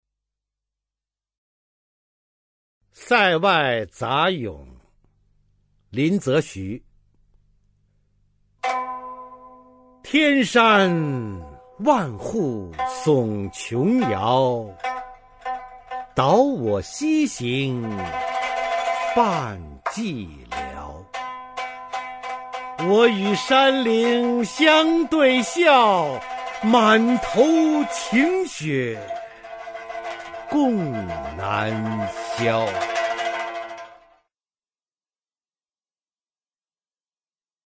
[清代诗词诵读]林则徐-塞外杂咏 配乐诗朗诵